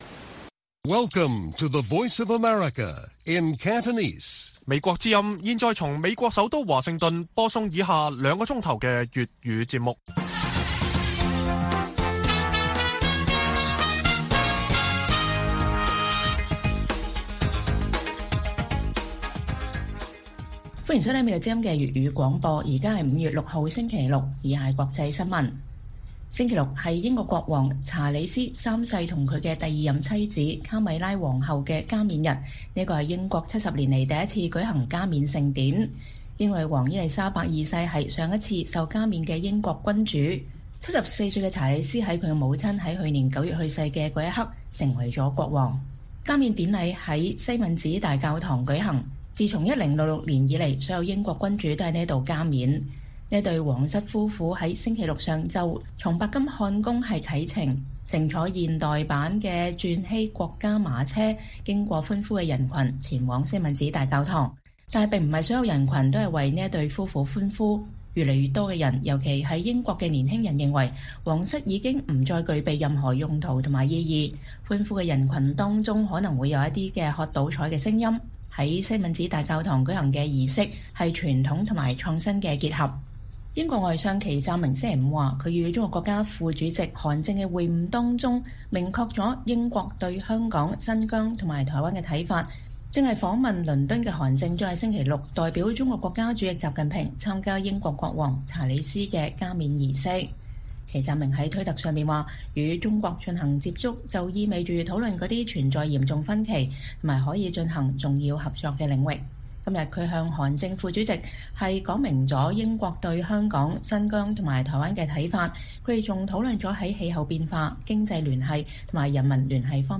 粵語新聞 晚上9-10點：英國舉行國王查理斯三世加冕禮